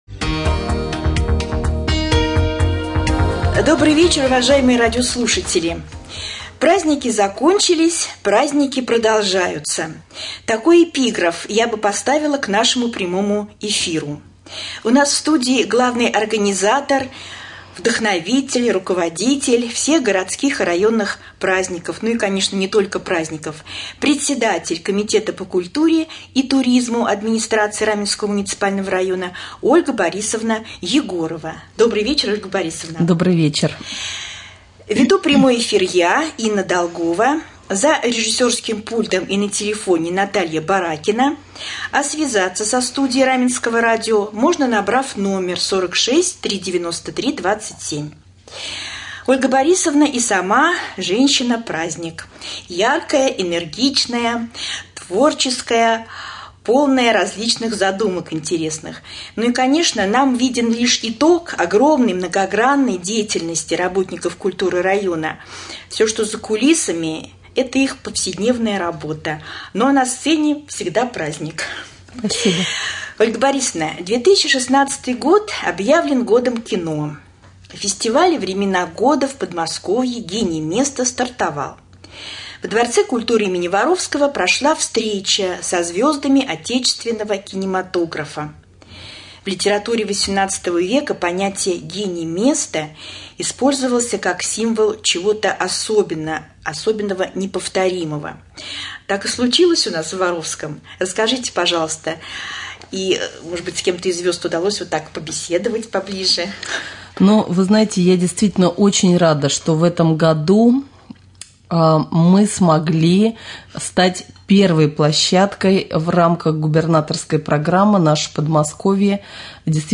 Прямой эфир с председателем комитета по культуре и туризму Ольгой Егоровой